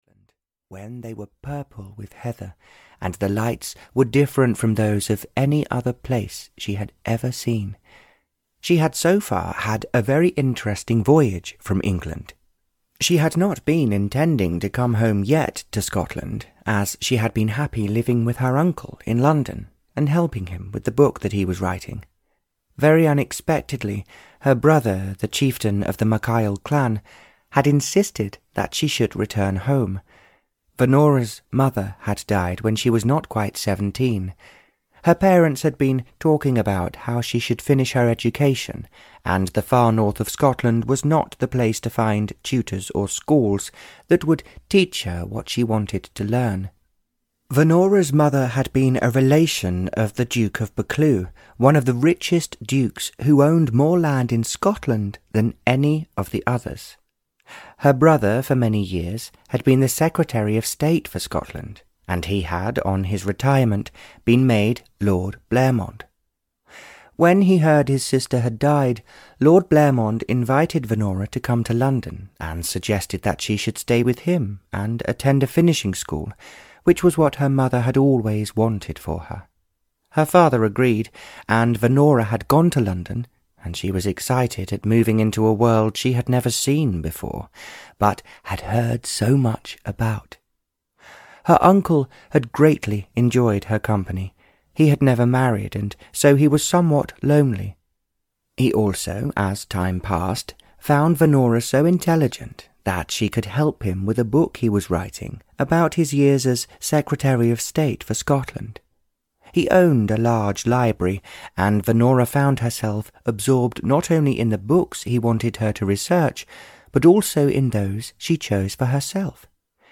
Audio knihaA Heart of Stone (EN)
Ukázka z knihy